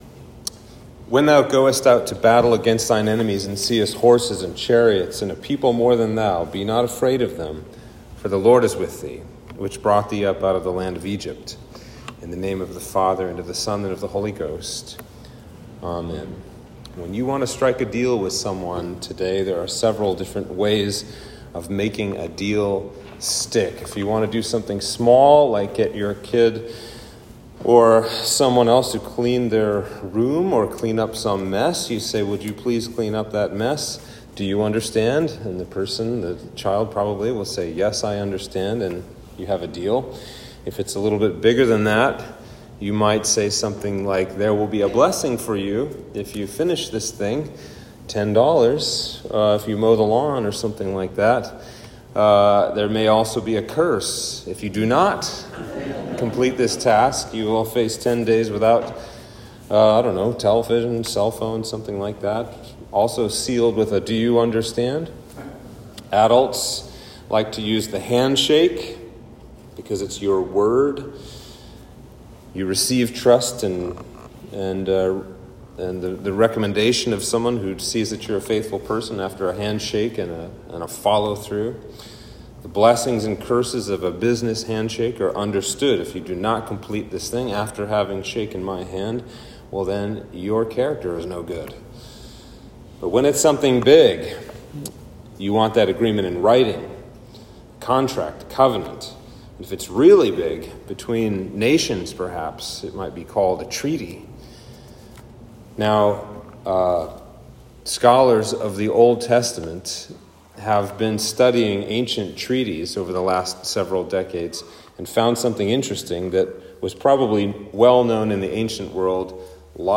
Sermon for Trinity 2